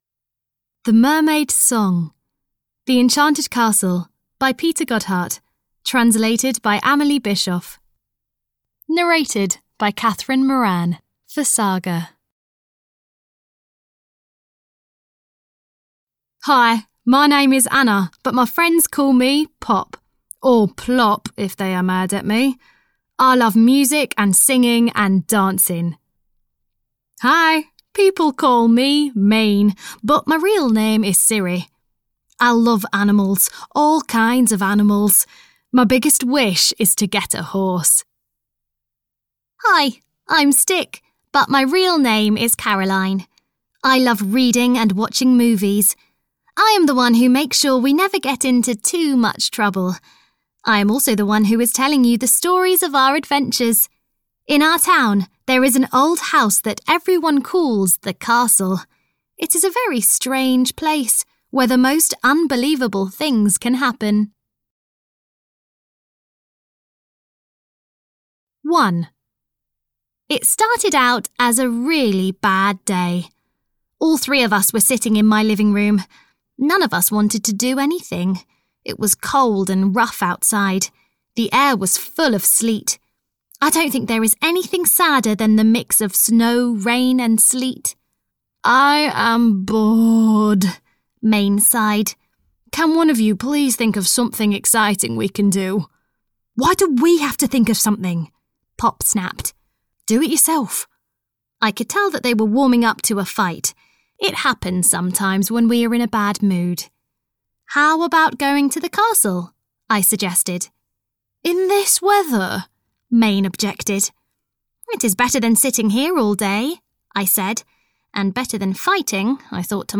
The Enchanted Castle 11 - The Mermaid's Song (EN) audiokniha
Ukázka z knihy